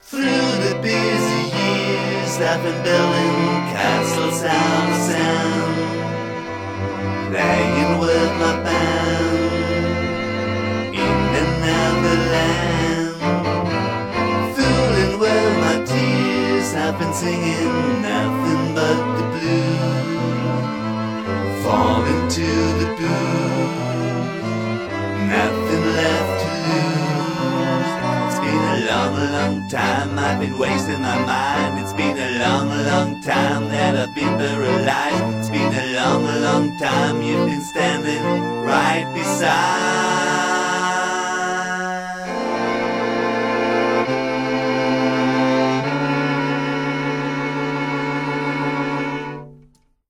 violins, fake viola, fake cello